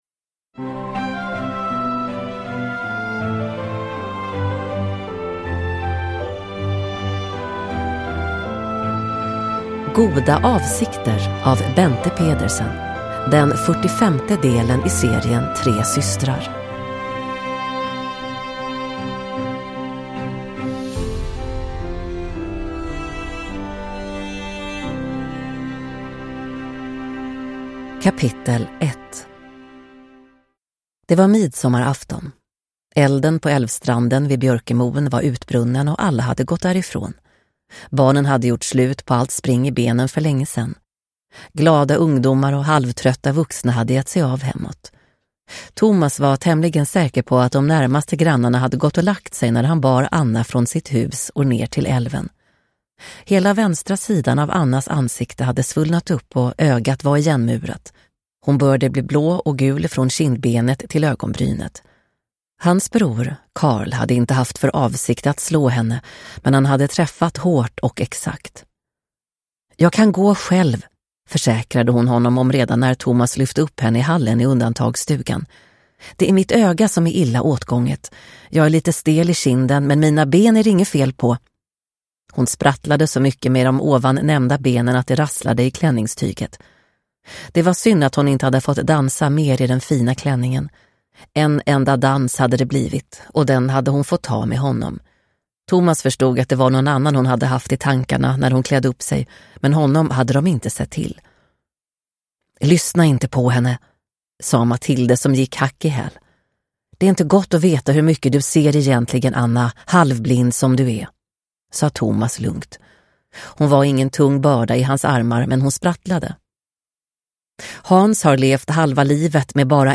Goda avsikter – Ljudbok